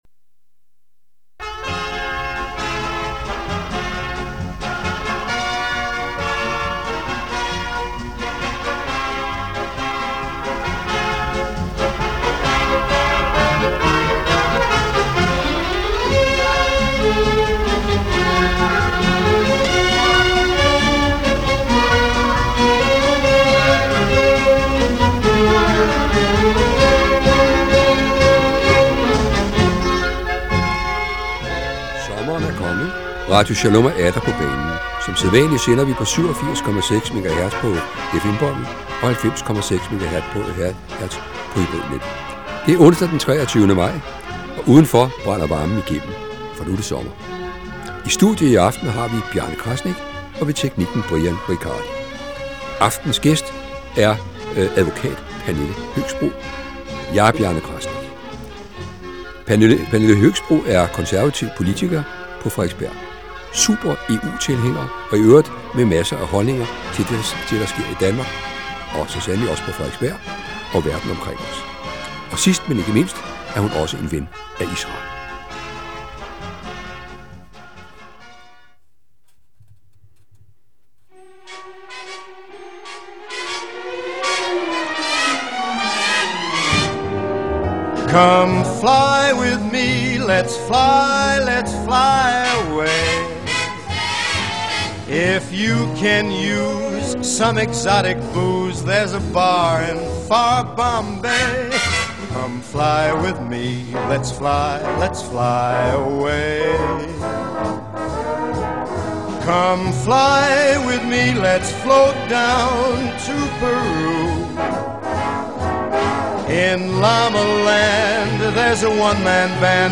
Beskrivelse:Interview